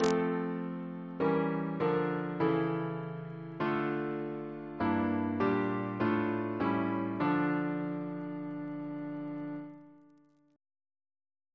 Single chant in D minor Composer: Sir John Goss (1800-1880), Composer to the Chapel Royal, Organist of St. Paul's Cathedral Reference psalters: ACB: 333; CWP: 68; RSCM: 179